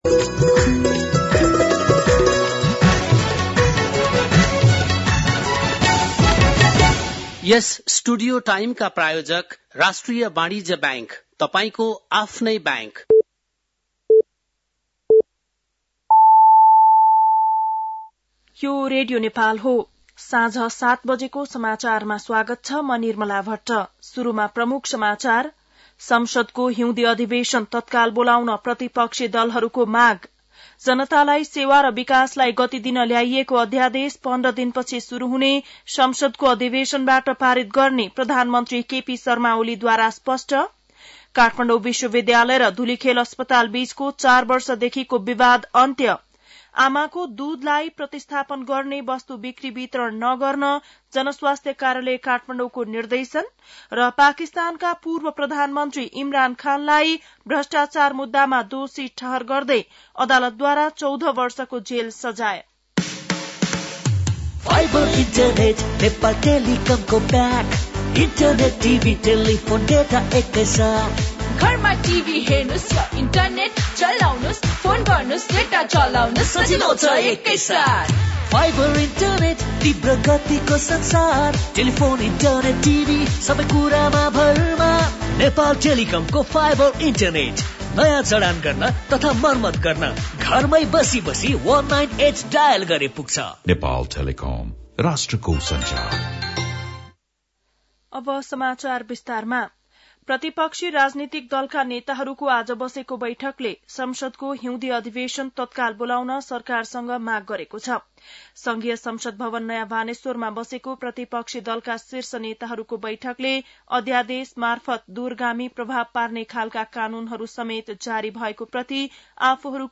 बेलुकी ७ बजेको नेपाली समाचार : ५ माघ , २०८१